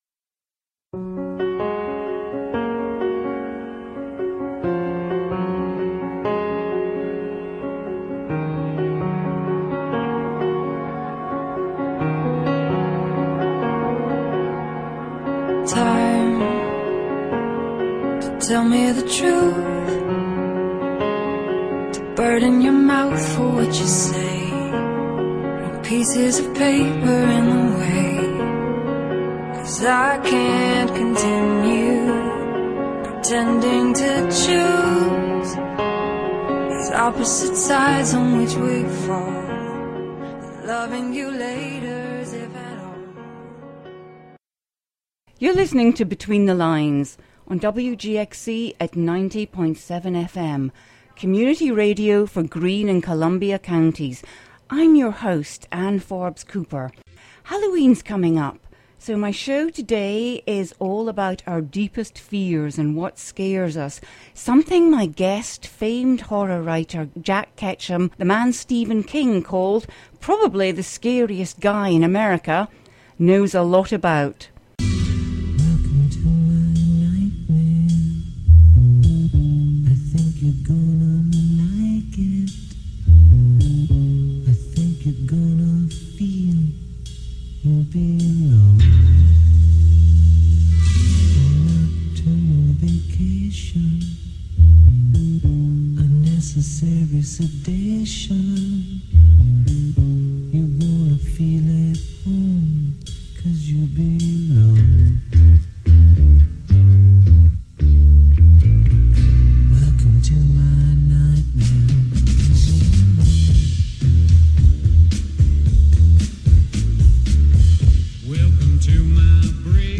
What better way to get in the mood for Halloween than to tune into a conversation with famed horror writer Jack Ketchum, winner of four Bram Stoker awards and author of twelve bone-chilling books, some of which have been made into cult films such as The Woman and The Girl Next Door. In anticipation of his talk, "Talking Scars," about turning your fears into fiction, at Beattie-Powers Place in Catskill on October 27 at 5 p.m., Ketchum discusses what makes a good horror story, the boundaries he will not cross, and what scares him.